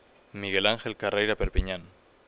You can listen to its pronunciation (in my Madrid accent).